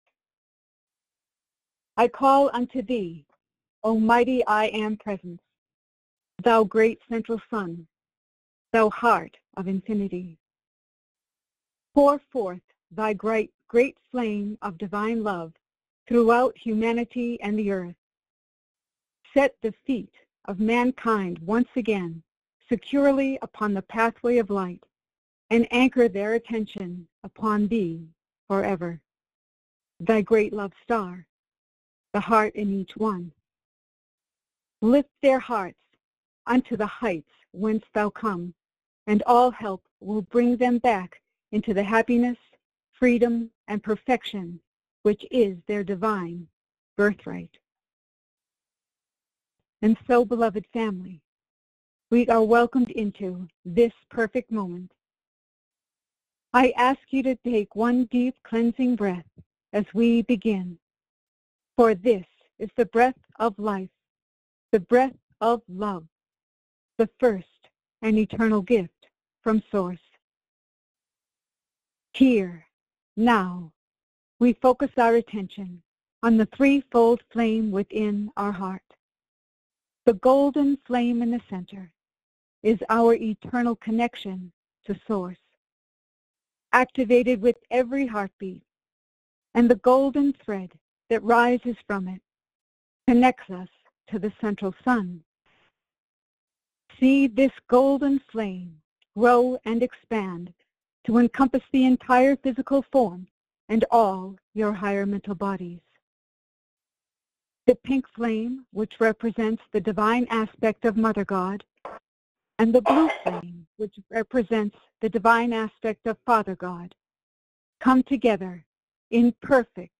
Join in group meditation with Lord Sananda ….